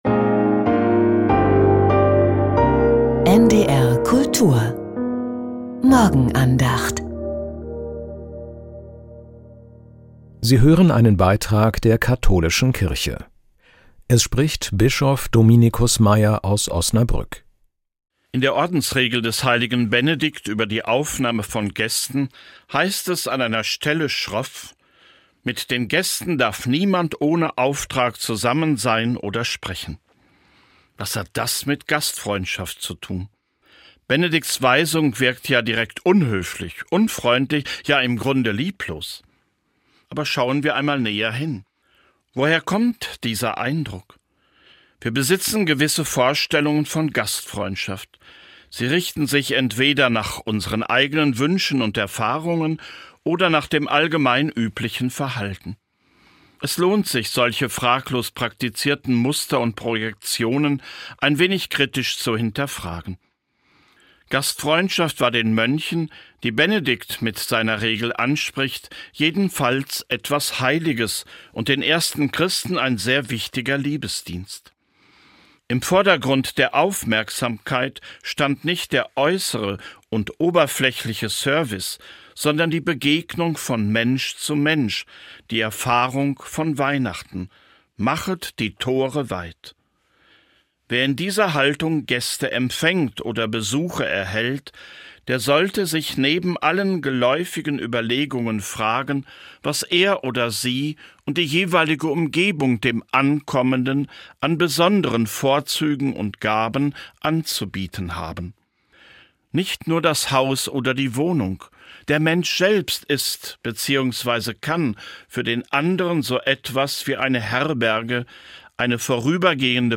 Ein Mensch kann für den anderen Herberge sein: Die Morgenandacht
mit Bischof Dominicus Meier.